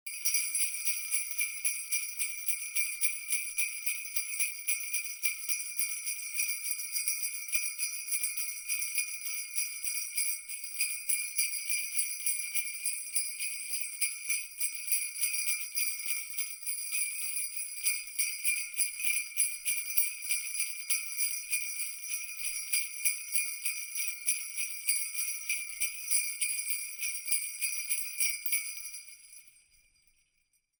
Звуки бубенцов
Звук бубенцов